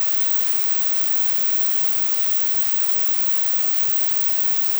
Dither
While today’s noize isn’t very musical, It will hopefully be educational.
Typically it lives far below audible levels.